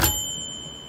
typerwriter_ding
bell ding old typerwriter sound effect free sound royalty free Sound Effects